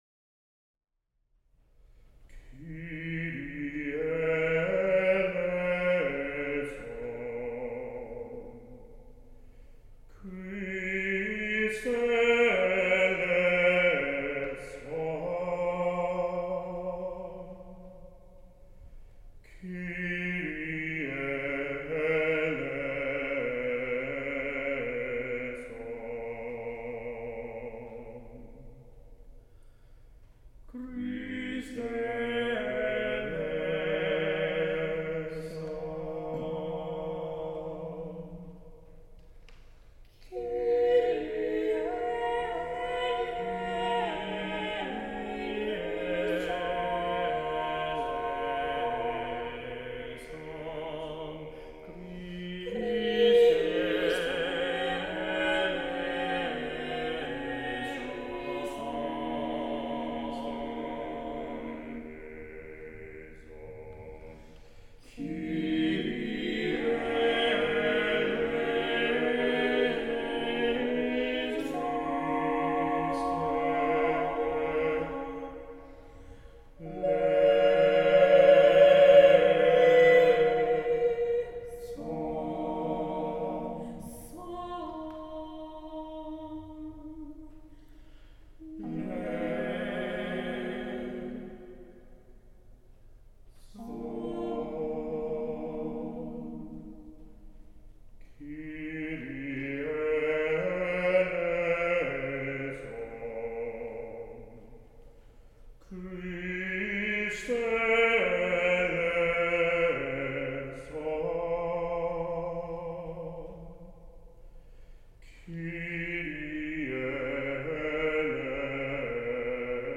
For SATB solo voices
[thumbnail of Recorded Performance] Audio (Recorded Performance)
As the title suggests, this is a short Mass in four settings (movements) composed for four solo voices (SATB):
The score evolved from a pedagogical study through which it was demonstrated to student composers how one could follow conventions of plainchant within a tonal stasis (without accidentals or modulations), yet still sound contemporary in nature, hopefully producing music that sounds timeless instead of being overtly modernistic or retrospective.
The opening plainchant (Kyrie) is written in Phrygian mode and the subtle presence of a sung grace note contemporises the rhythmic and harmonic flavour of the phrase. The simple use of a compound interval for the bass in the first chorus also helps to contemporise the soundworld along with the use of fourth-based harmony and the linear use of whole-tones.